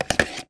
Вибротон с тремя виброволнами